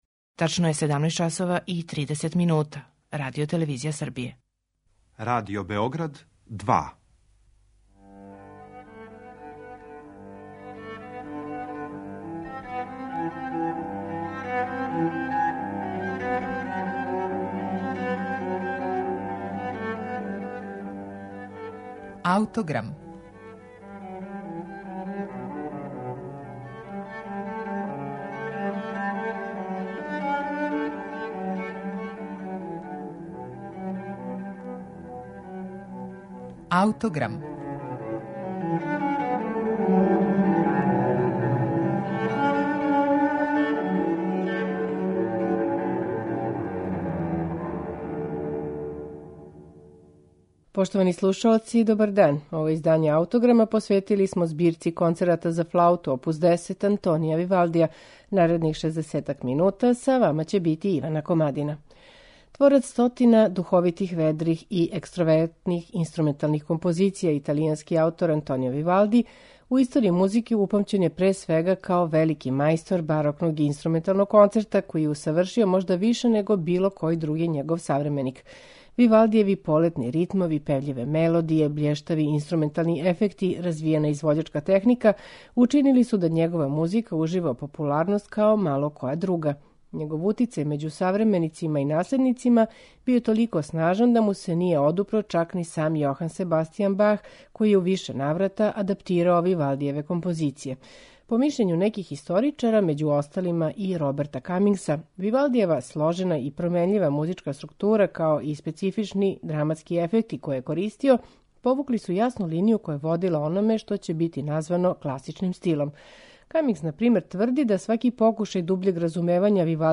Тако, у концерту број 3 у Де-дуру, који носи назив "Ил гарделино" - "Жута зеба", солистичка деоница флауте мноштвом трилера и покретљивих ритмова дочарава живахни пев ове мале птице, док концерт број 1 у Еф-дуру, са поднасловом "Олуја на мору", тонски слика узбуркано кретање великих водених маса.
на оригиналним барокним инструментима
уздужна флаута
попречна флаута